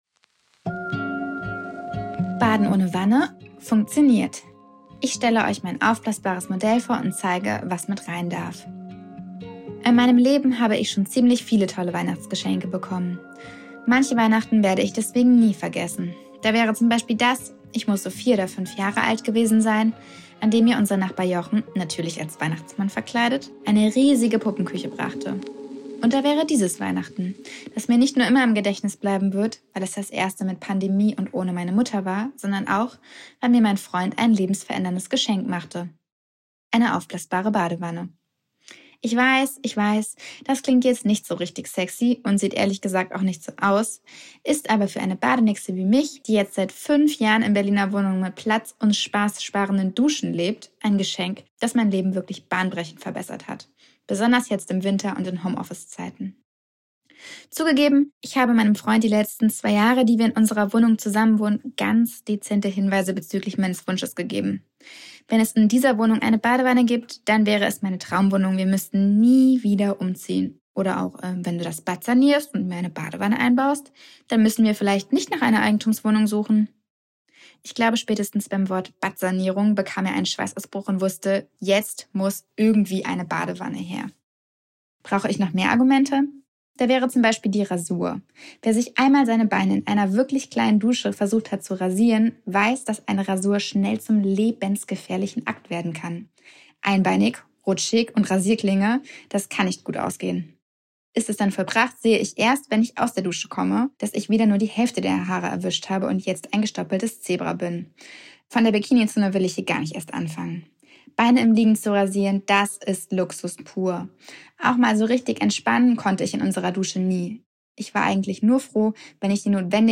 Audioartikel